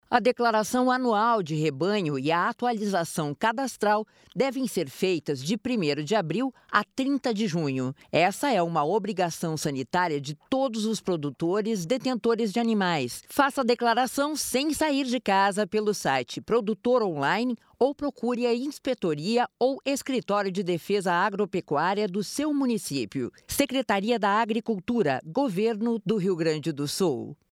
Spot de rádio sobre a Declaração Anual de Rebanho 2024